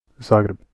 Zagreb (/ˈzɑːɡrɛb/ ZAH-greb[7] Croatian: [zǎːɡreb]
Hr-Zagreb.ogg.mp3